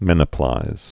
(mĕnĭ-plīz)